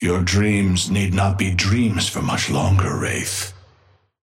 Patron_male_ally_wraith_start_02.mp3